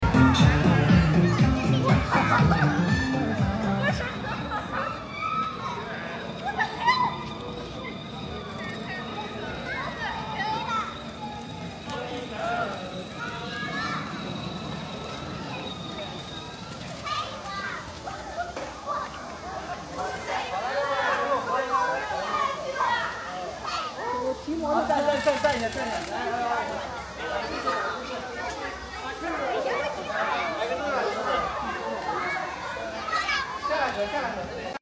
Electric motorcycle. Electric bike sound effects free download